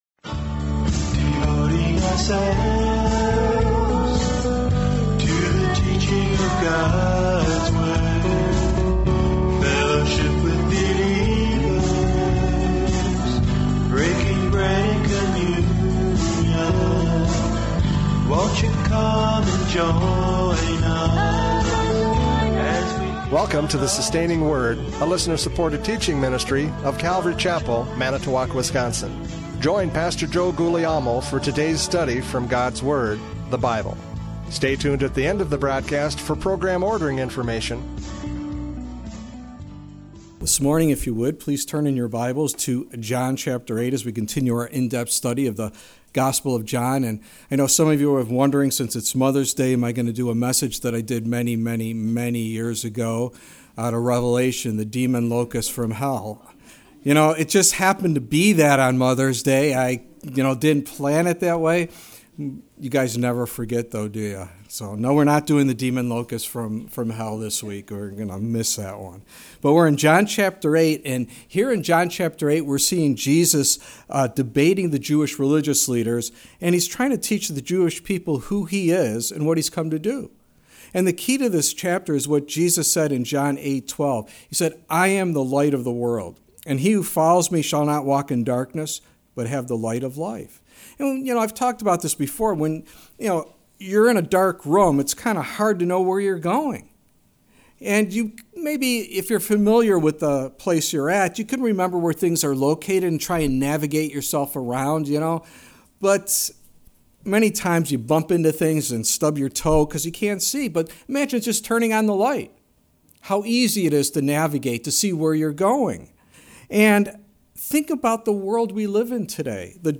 John 8:39-59 Service Type: Radio Programs « John 8:31-38 Freedom in Christ!